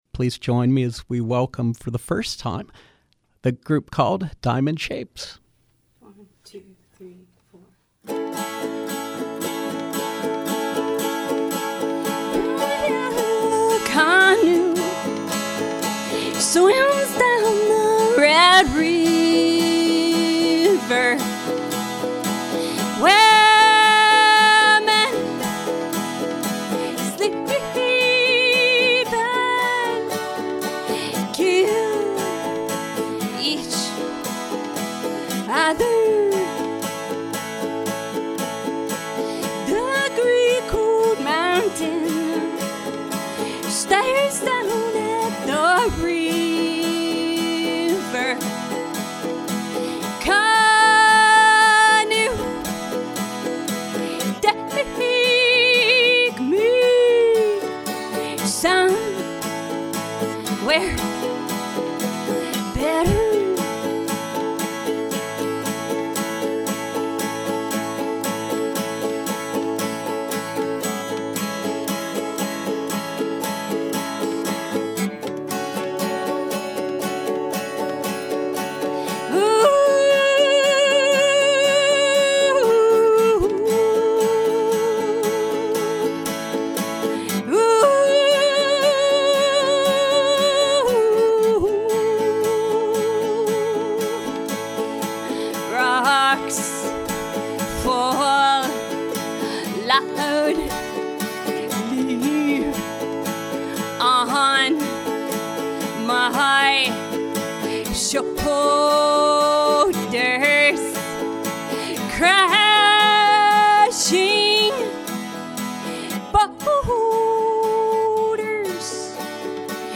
Live music with contemporary folk band